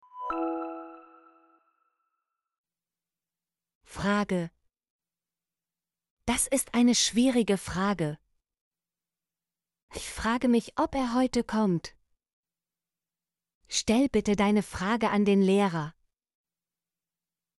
frage - Example Sentences & Pronunciation, German Frequency List